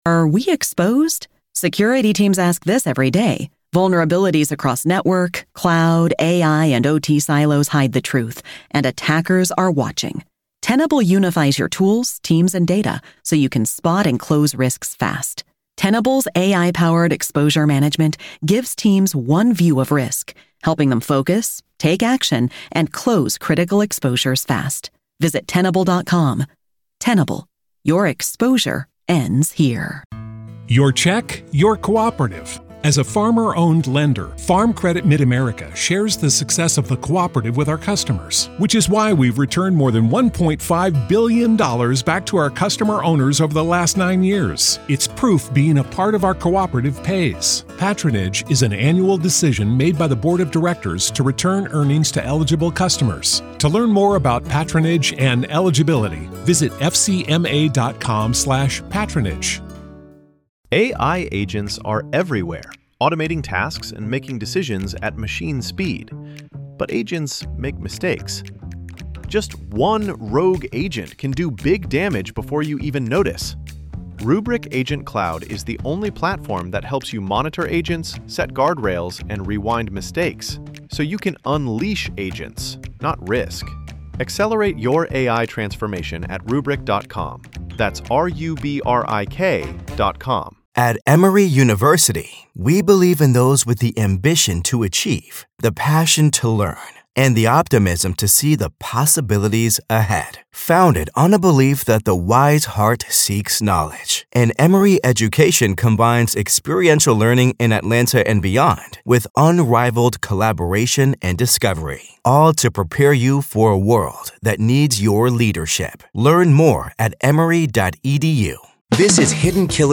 Whether you are a dedicated follower of true crime, or an everyday listener interested in the stories shaping our world, the "Week in Review" brings you the perfect balance of intrigue, information, and intelligent conversation.